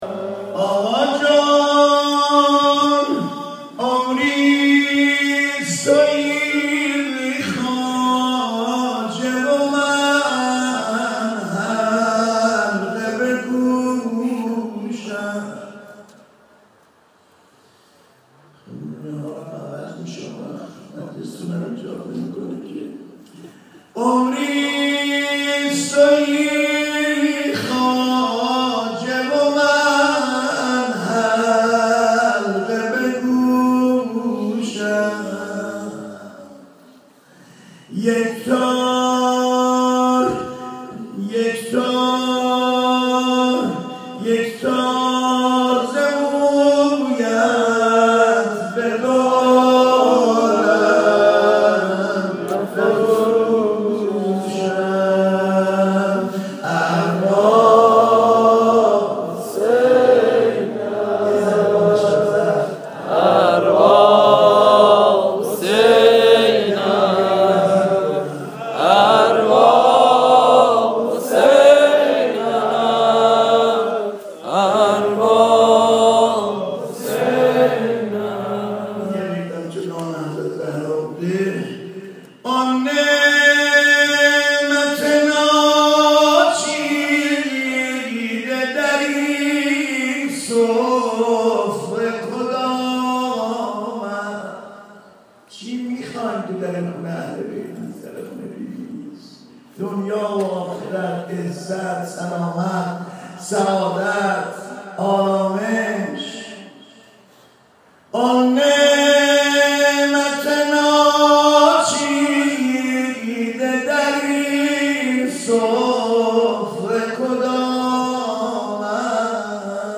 در ایوان شمس برگزار شد
هشتاد و نهمین جلسه از "کلاس مجردها" روز یکشنبه ۲۸ اردیبهشت ماه در تالار ایوان شمس با حضور حاج علی انسانی برگزار شد.
حاج علی انسانی مداح ، شاعر و پیرغلام اهل بیت (ع) با حضور در این جلسه دقایقی نیز برای حاضران مداحی کرد.
صوت مداحی حاج علی انسانی